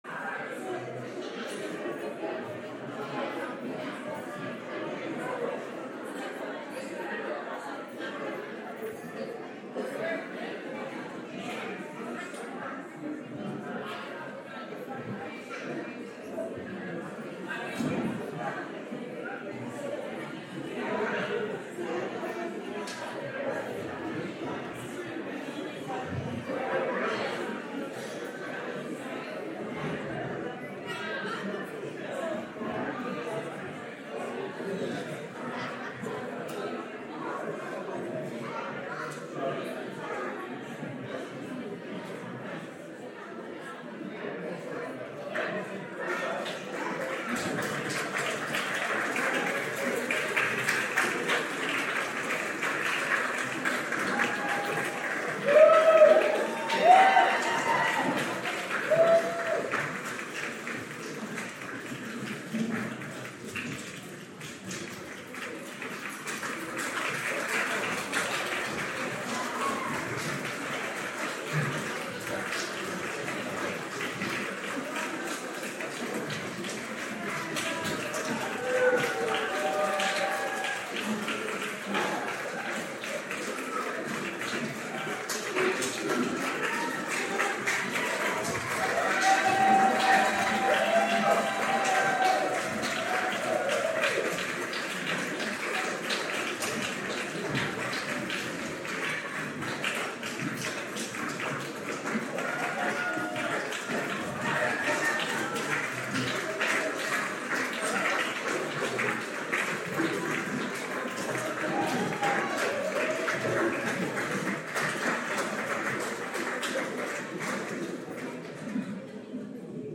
Live from Hudson Hall: Community Choir live from Hudson Hall (Audio)